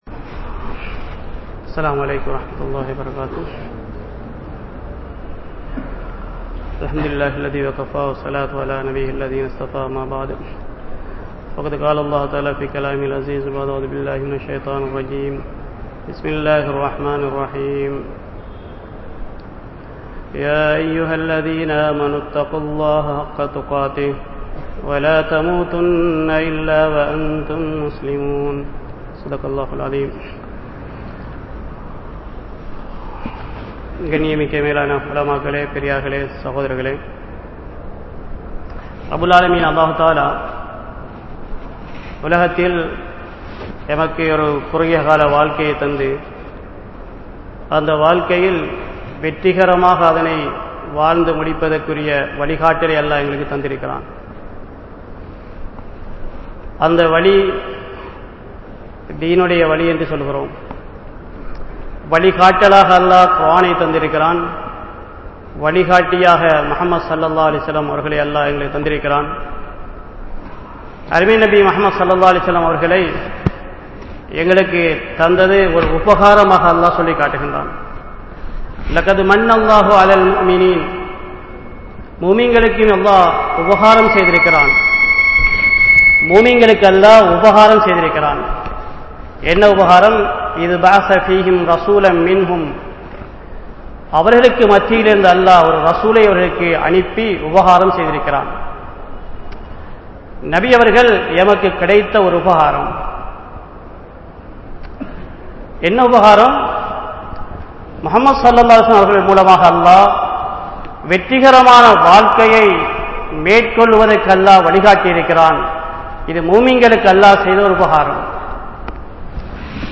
Nabi Valium Indraya Naahareehamum (நபி வழியும் இன்றைய நாகரீகமும்) | Audio Bayans | All Ceylon Muslim Youth Community | Addalaichenai
Grand Jumua Masjith